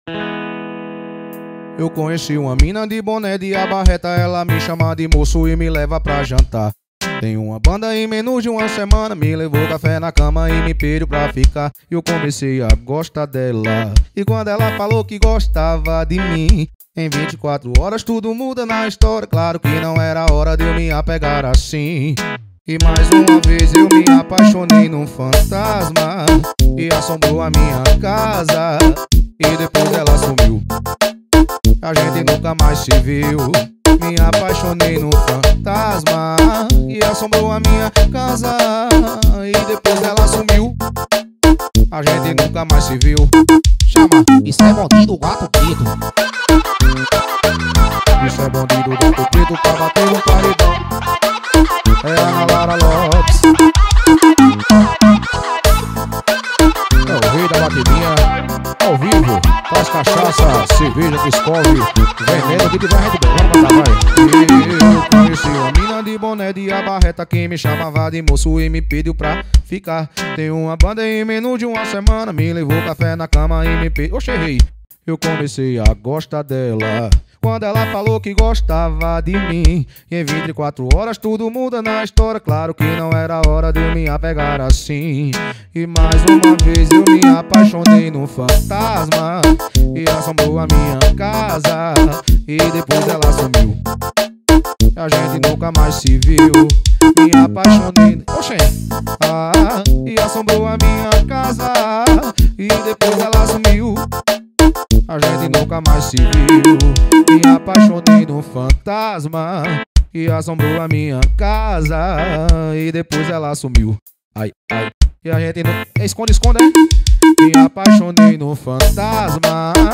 2025-01-31 19:59:49 Gênero: Arrocha Views